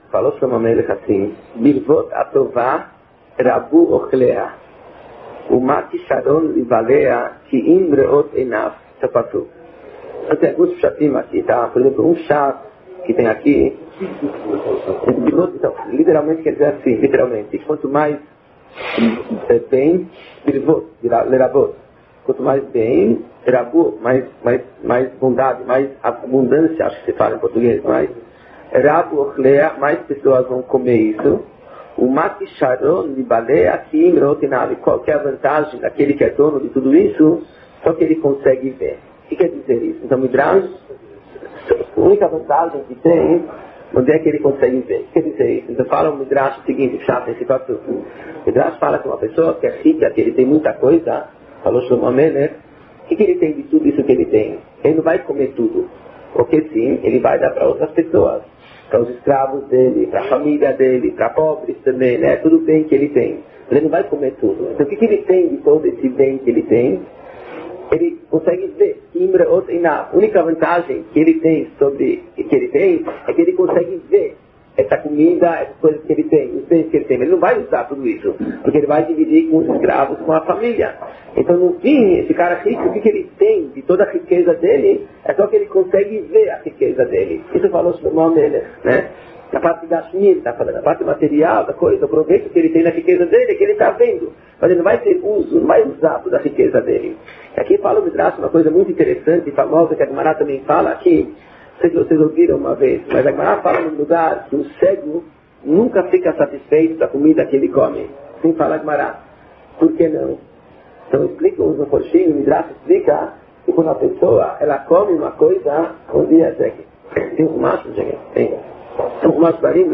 Shiur antigo remasterizado – Desculpe a baixa qualidade.